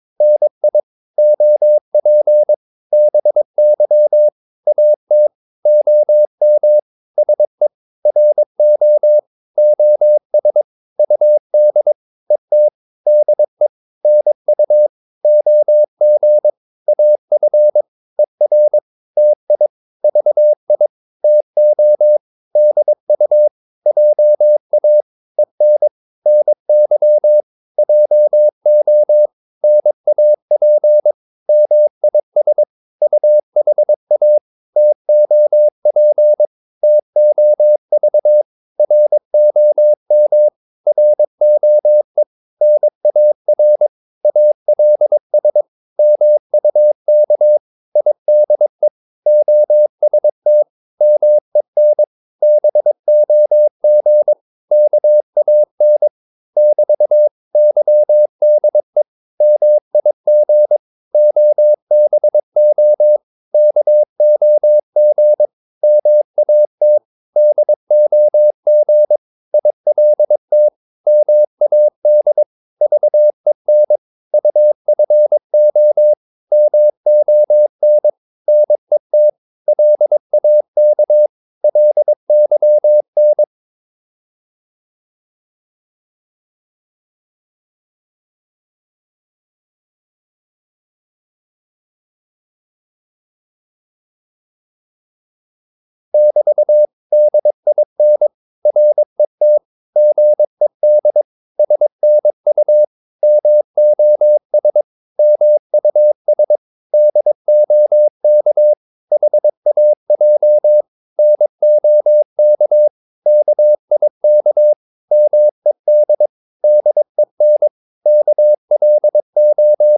Korte danske ord 22wpm | CW med Gnister
Korte ord DK 22wpm.mp3